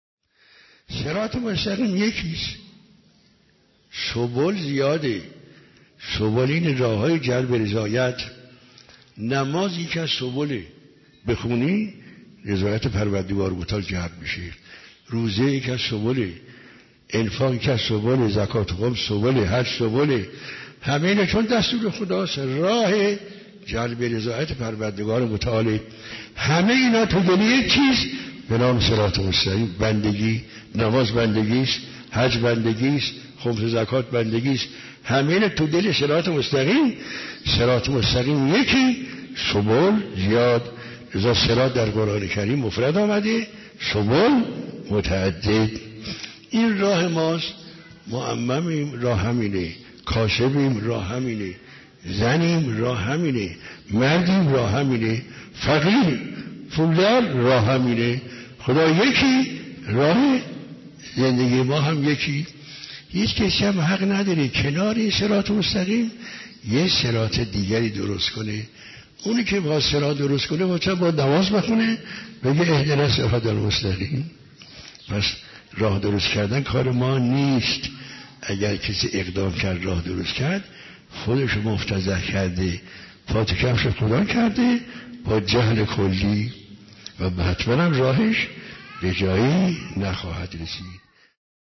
علمی فرهنگی
اخلاق و معنویت